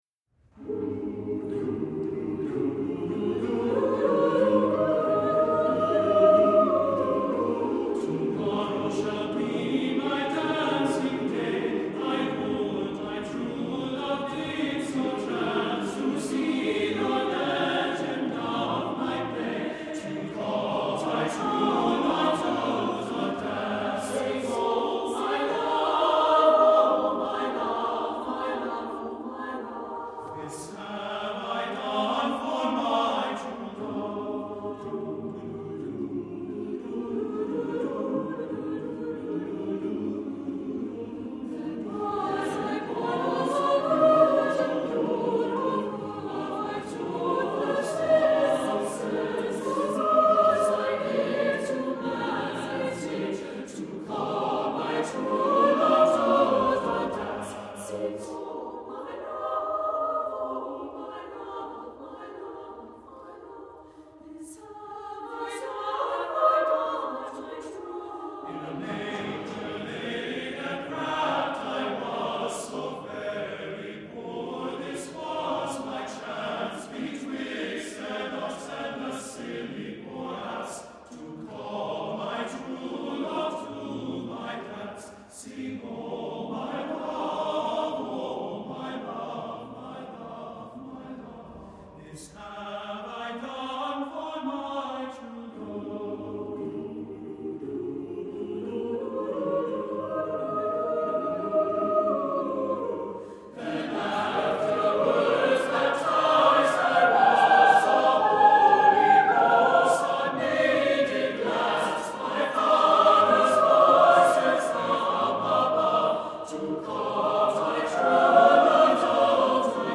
Voicing: SSATBB a cappella